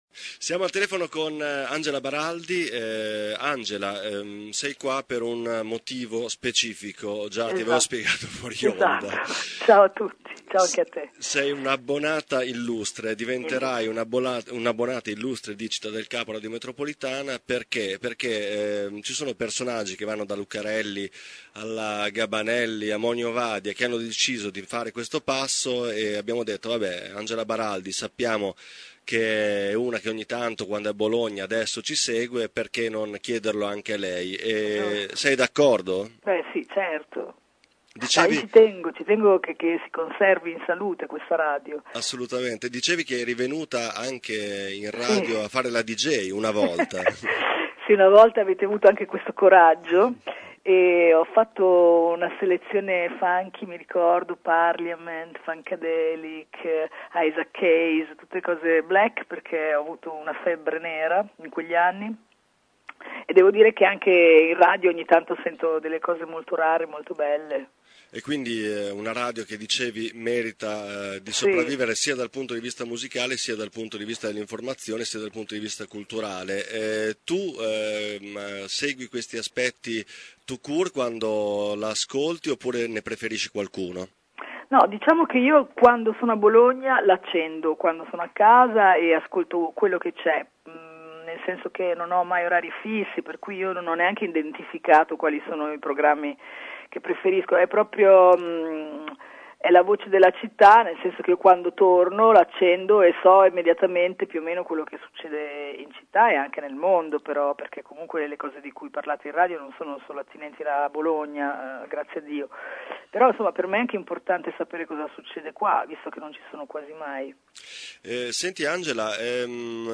L’attrice e musicista bolognese si è abbonata oggi alla radio. Ascolta l’intervista: baraldi_-sito